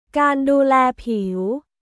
การดูแลผิว　カーン・ドゥー・レー・ピウ